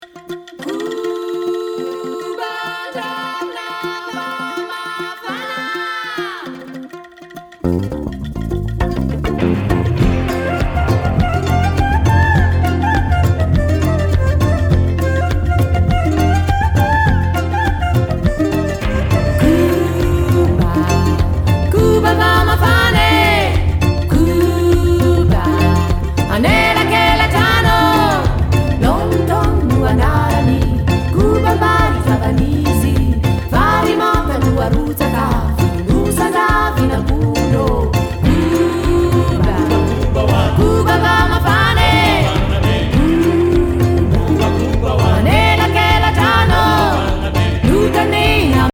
ジャワや東南アジア風味の味付けが◎